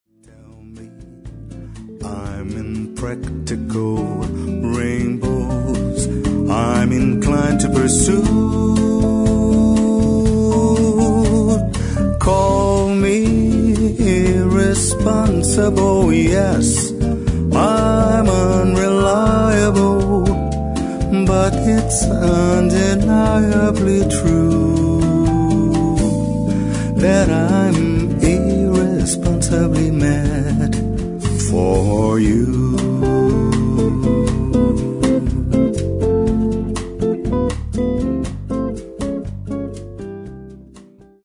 arranjados ou transformados em Bossa Nova